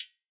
DDWV HAT 5.wav